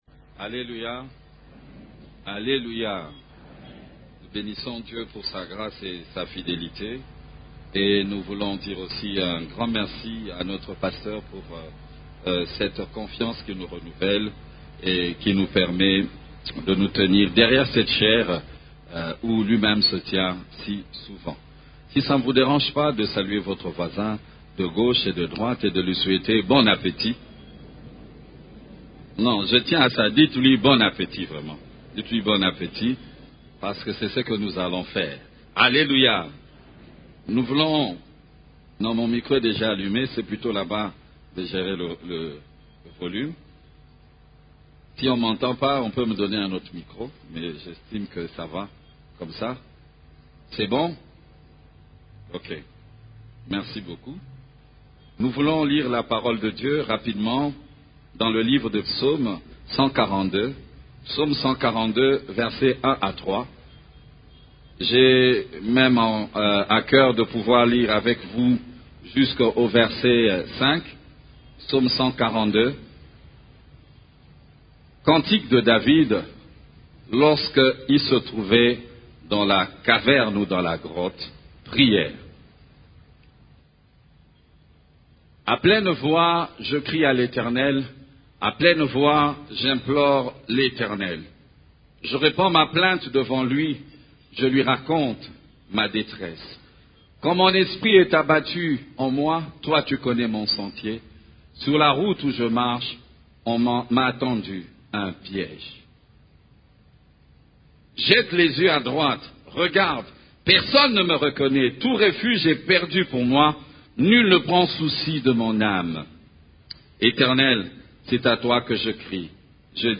CEF la Borne, Culte du Dimanche, Criez à l'Eternel dans la détresse